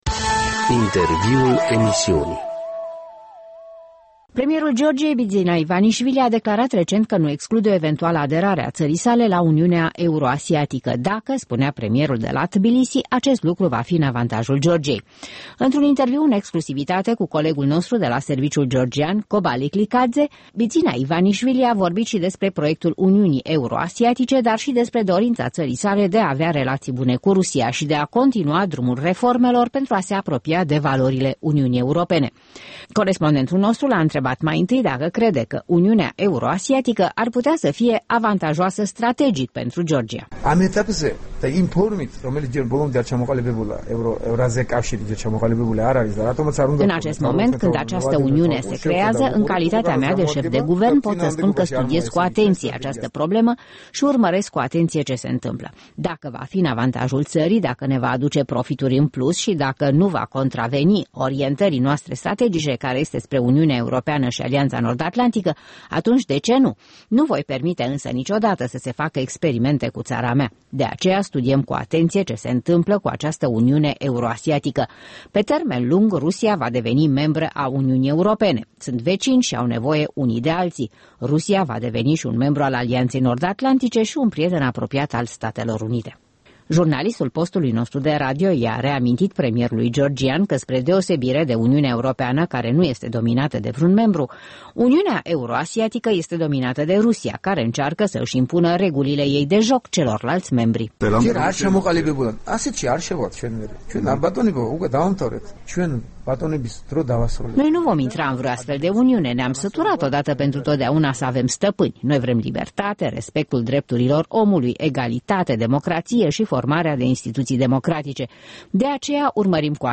Primul ministru al Georgiei, Bidzina Ivanișvili, răspunde întrebărilor Europei Libere